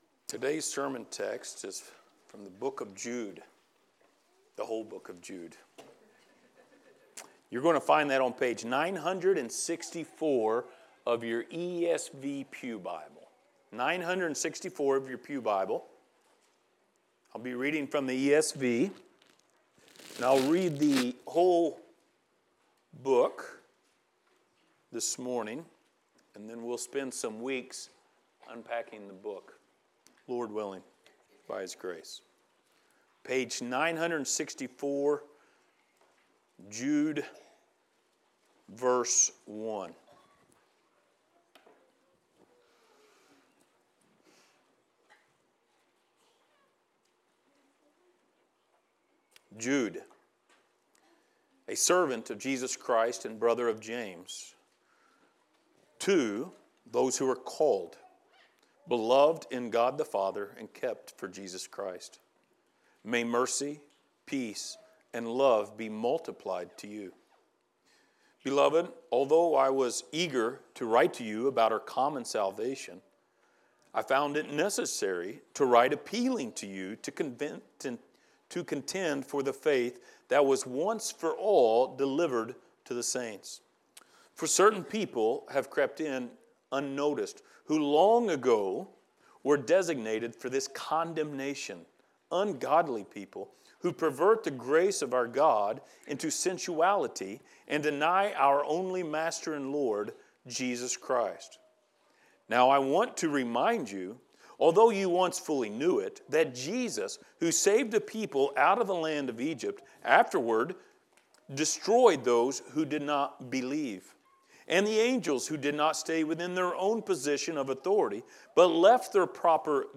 Passage: Jude 1-2 Service Type: Sunday Morning